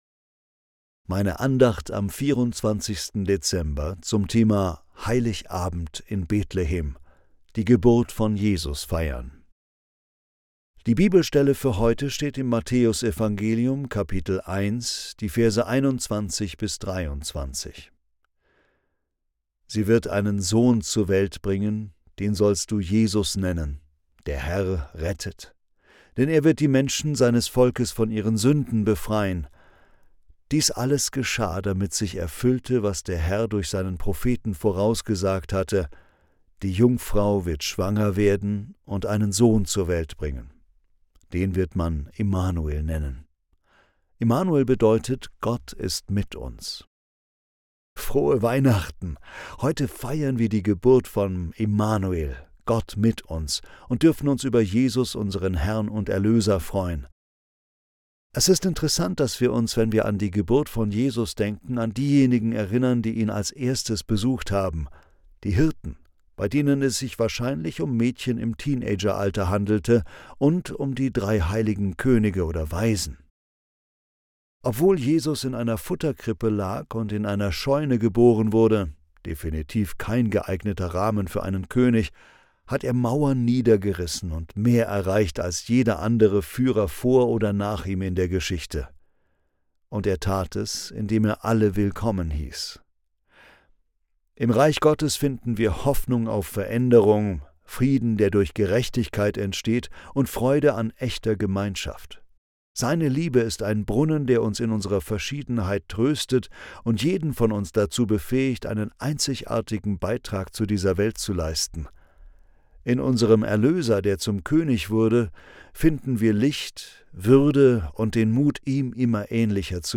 Andacht zum 24. Dezember